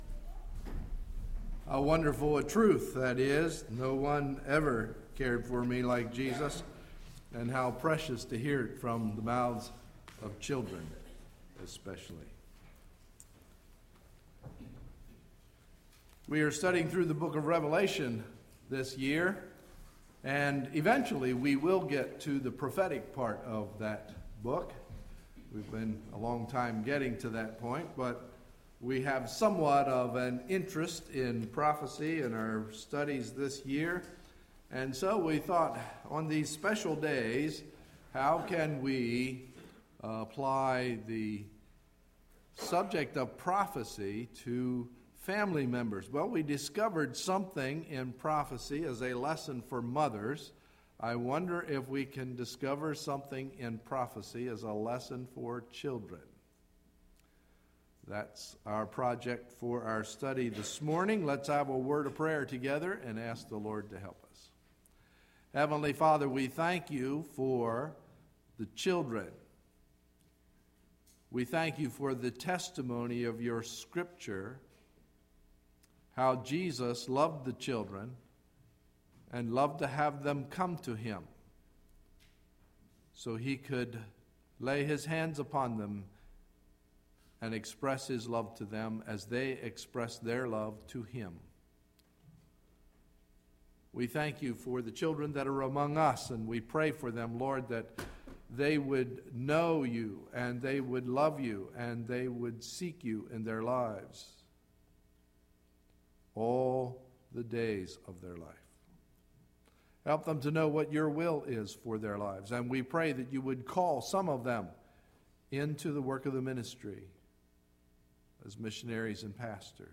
Sunday, May 22, 2011 – Morning Message